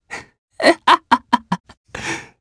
Ezekiel-Vox_Happy2_jp_b.wav